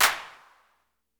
Waka Clap 3 (6).wav